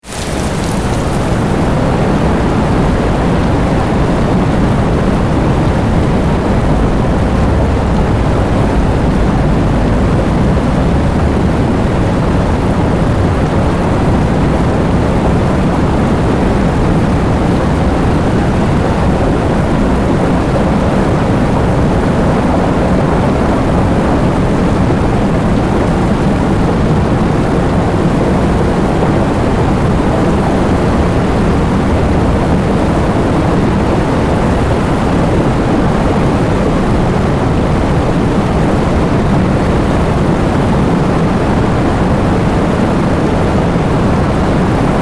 Download Heavy Rain sound effect for free.
Heavy Rain